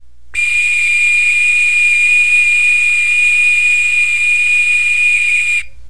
简单的声音 " 塑料平底锅长笛
描述：从一个廉价的儿童塑料泛音笛发出的短促的声音，这种类型的泛音笛是作为一个适度的聚会礼物发放的。这个声音不像传统的泛音笛，而像茶壶沸腾，磨坊哨子或塑料光哨子。
Tag: 水壶 排箫 蒸汽 哨子